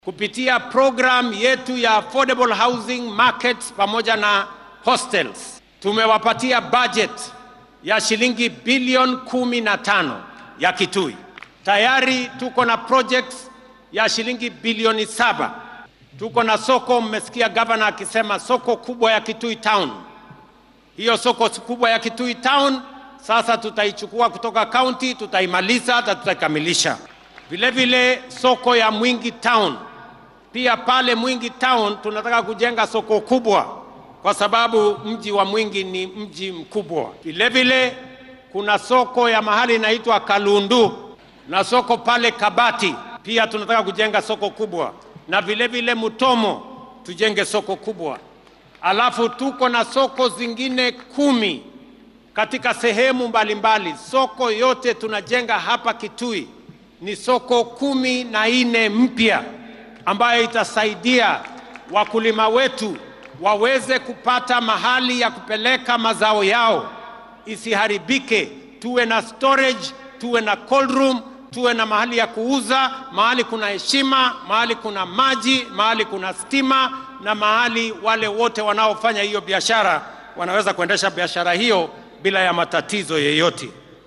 Xuska Mashuja Day ayaa maanta lagu qabtay garoonka Ithookwe ee ismaamulka Kitui.
Dhinaca kale madaxweyne Ruto ayaa ka hadlay mashaariic kala duwan oo ay ka mid yihiin biyo galin,casriyaynta garoon lagu ciyaaro,suuqyo koronto iyo wadooyiin in uu ka hirgalin doono dowlad deegaanka Kitui oo maanta martigalisay xuska Mashuja Day.